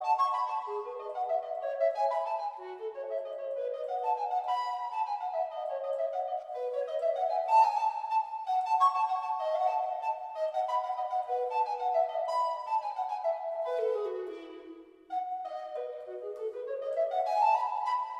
下一刻，柔和的笛声在空气中流淌开来，轻盈而真挚，如微风拂过草原。
竖笛子.mp3